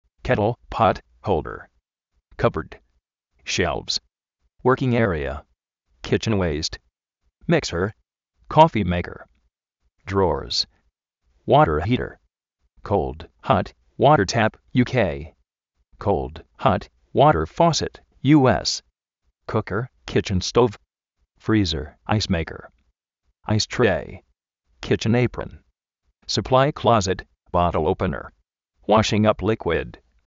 kétl (pot) jólder
kápbo:rd
shélvs
uérkin érea
kítchen uéist